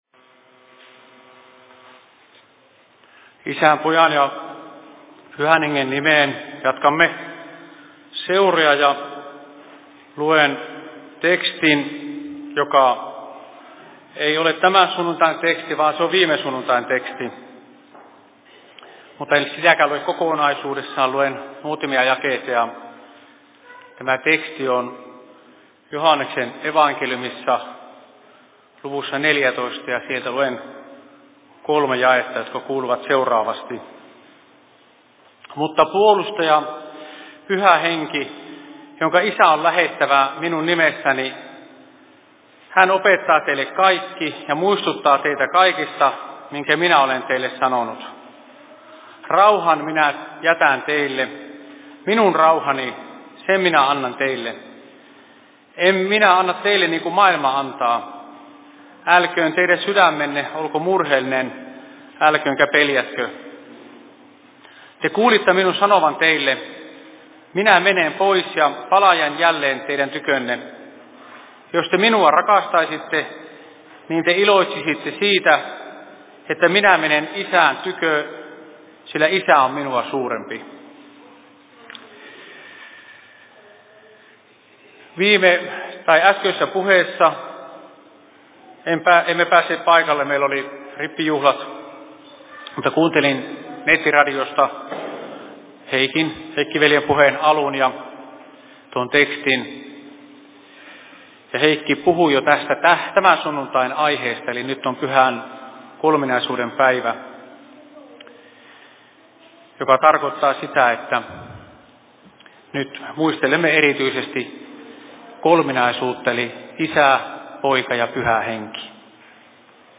Seurapuhe Kajaanin RY:llä 15.06.2025 17.30
Paikka: Rauhanyhdistys Kajaani